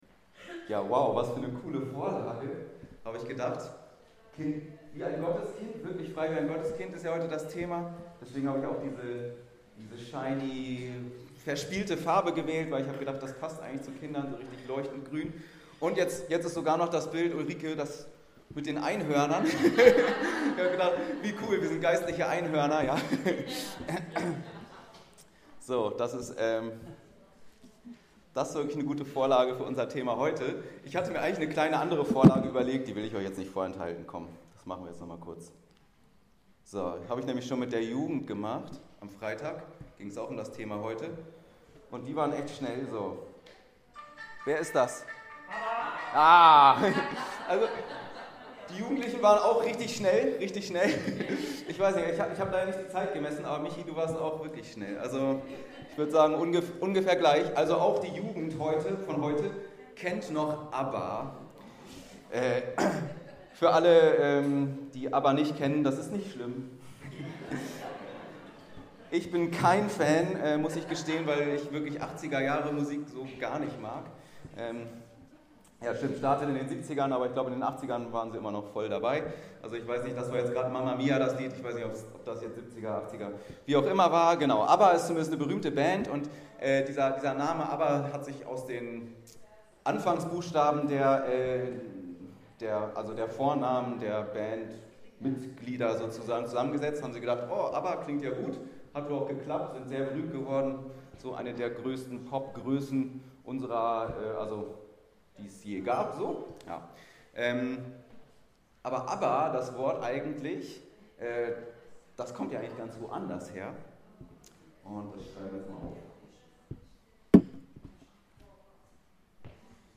Frei wie ein Gotteskind ~ Anskar-Kirche Hamburg- Predigten Podcast